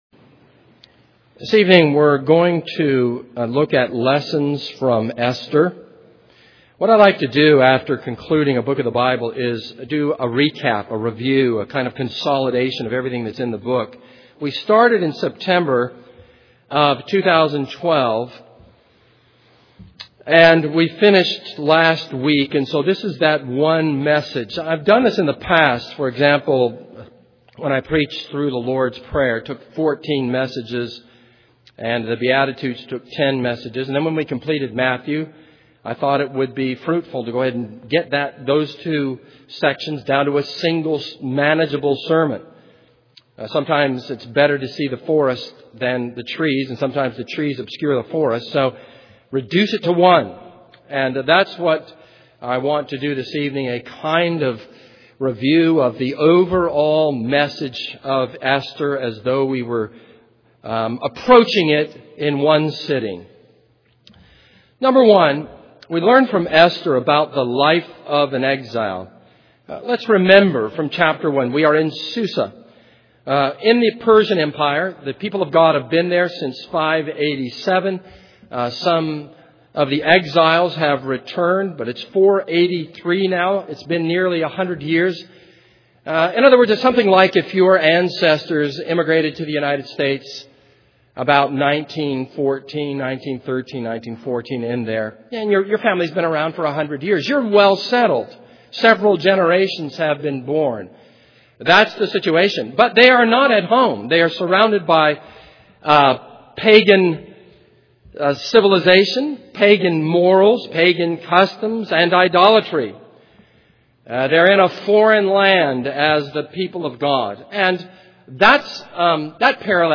This is a sermon on Esther 10.